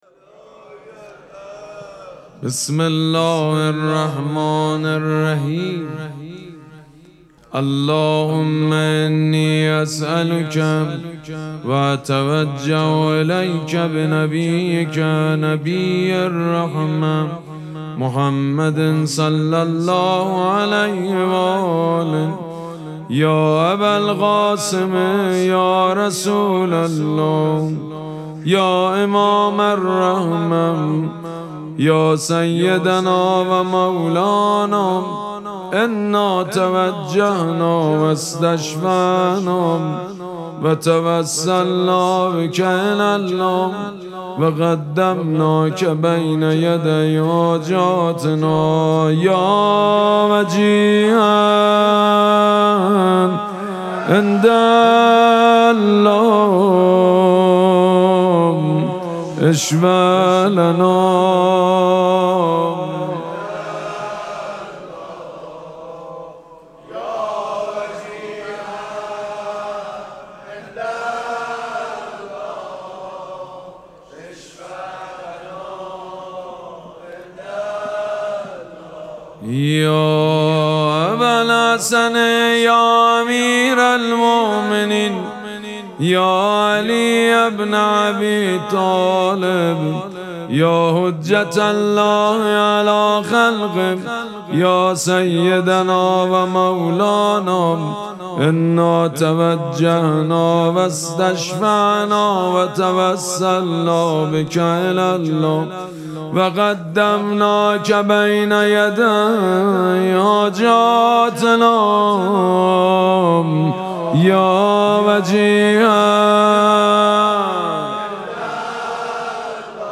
مراسم مناجات شب هجدهم ماه مبارک رمضان سه‌شنبه‌ ۲۸ اسفند ماه ۱۴۰۳ | ۱۷ رمضان ۱۴۴۶ حسینیه ریحانه الحسین سلام الله علیها
مداح حاج سید مجید بنی فاطمه